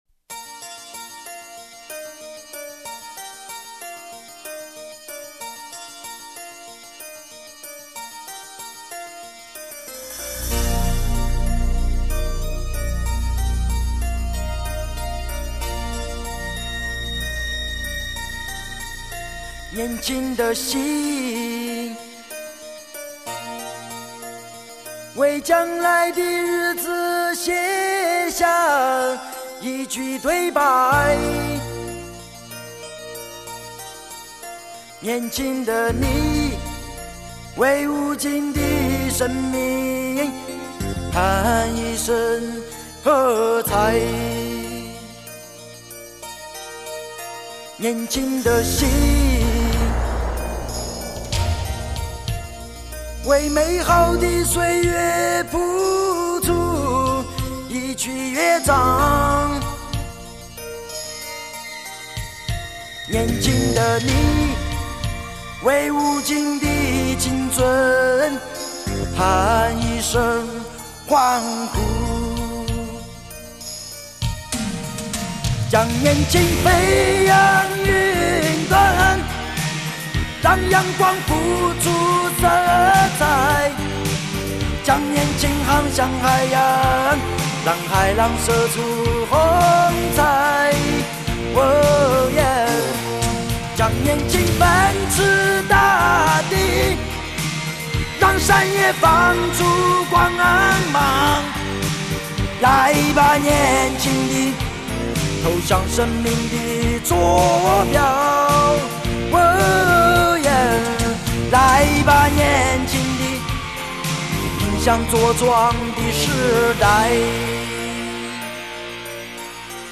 他真挚的情感，不修饰地嘶哑和他不刻意流露的狂嚣